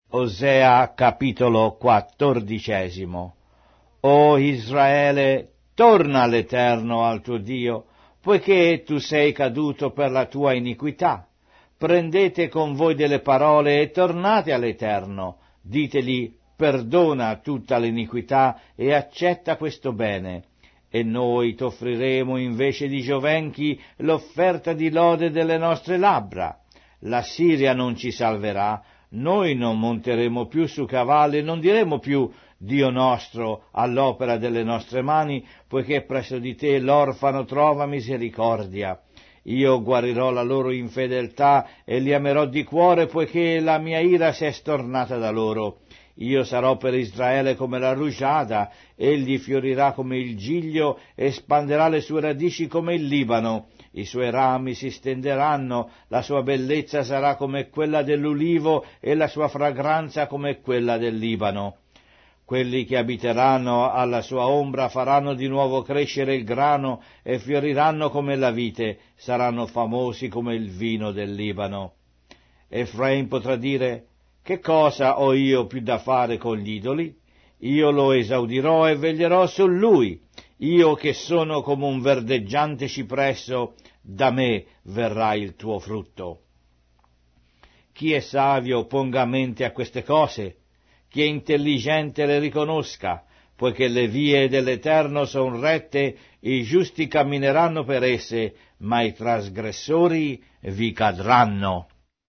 Sacra Bibbia - Riveduta - con narrazione audio - Hosea, chapter 14